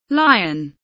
lion kelimesinin anlamı, resimli anlatımı ve sesli okunuşu